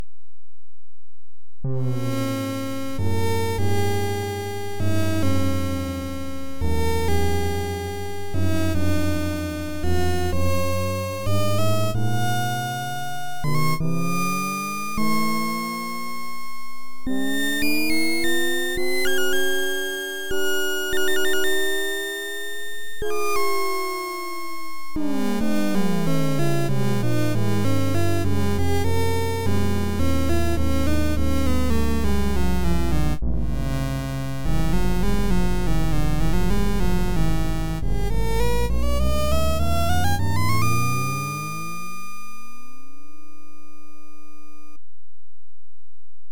some short recordings he made using the first release of the SID emulator.